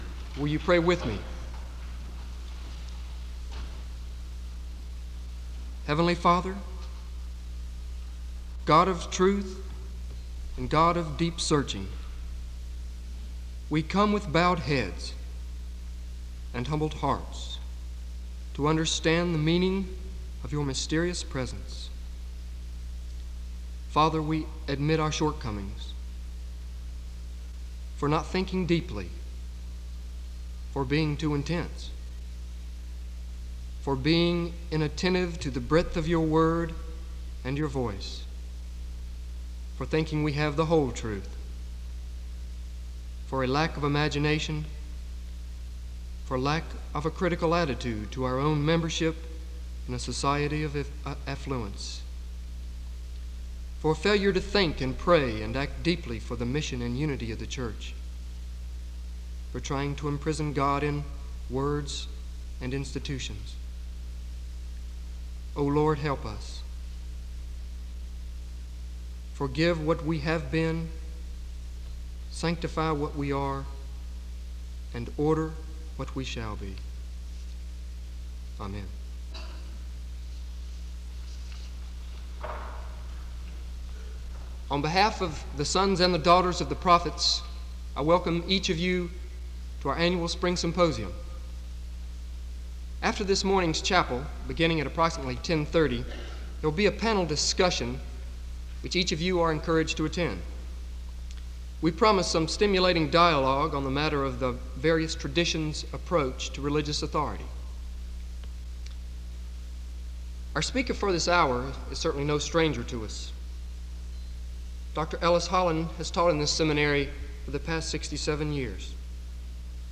SEBTS Chapel
SEBTS Chapel and Special Event Recordings